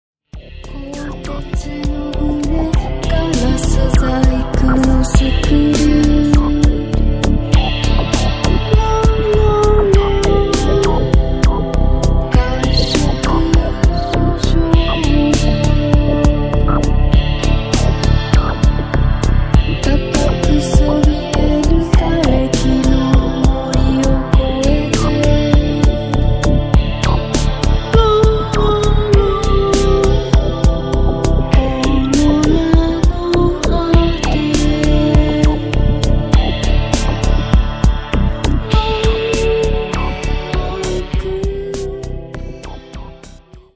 Cyberpunk + Industrial + Goth + Deathrock